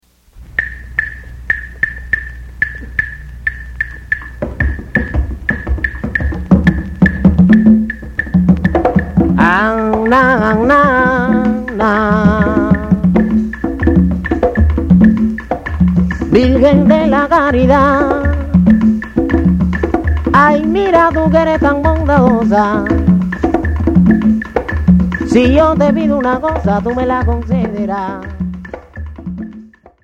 Темп: 122 bpm